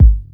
Game_Kick_1.wav